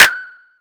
• Hand Clap F# Key 11.wav
Royality free clap sample - kick tuned to the F# note. Loudest frequency: 2946Hz
hand-clap-f-sharp-key-11-sZb.wav